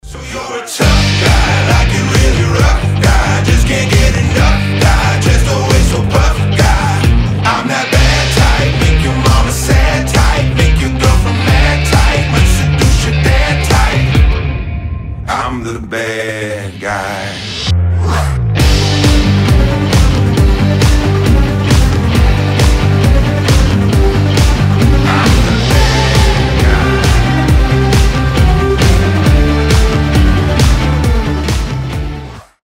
• Качество: 320, Stereo
мужской голос
брутальные
Cover
Alternative Rock
Хороший альтернативный кавер на известный трек